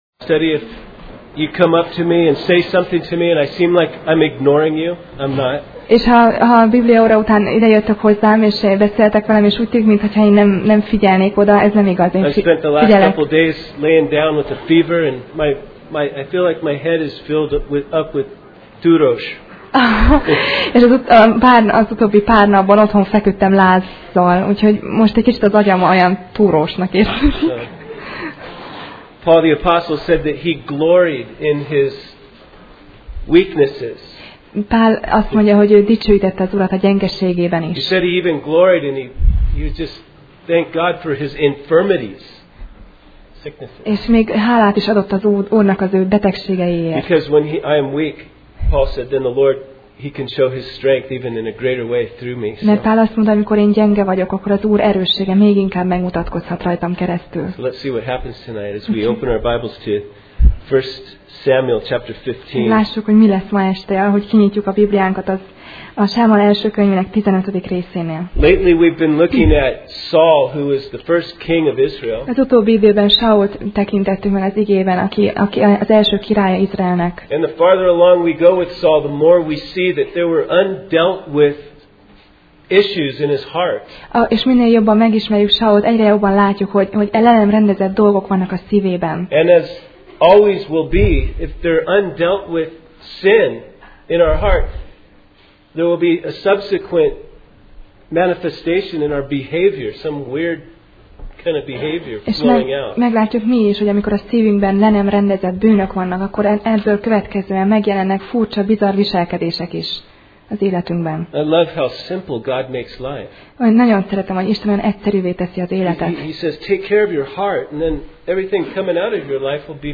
1Sámuel Passage: 1Sámuel (1Samuel) 15:1-31 Alkalom: Szerda Este